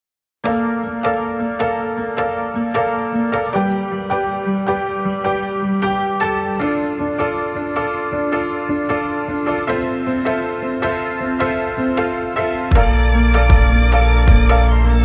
Perkusja
Gitary
Instrumenty klawiszowe, bass, instr. perkusyjne, piano